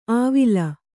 ♪ āvila